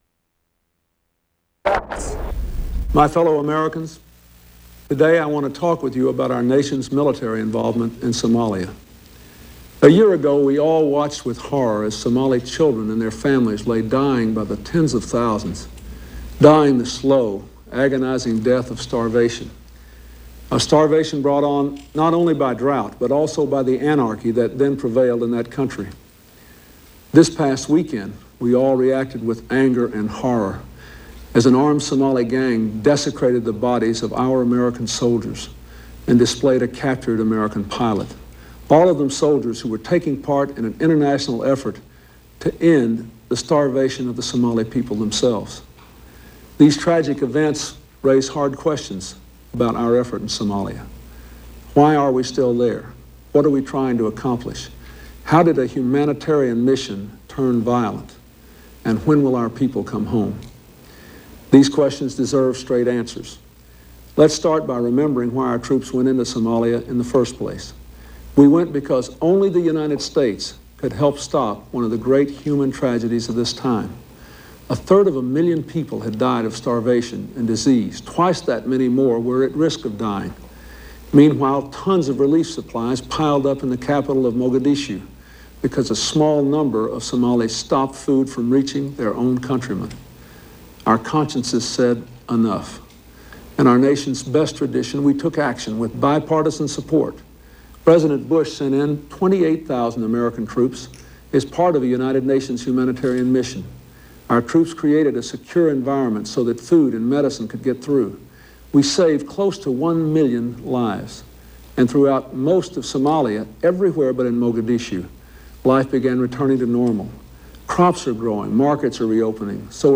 U.S. President Bill Clinton speaks to the nation on his policy in Somalia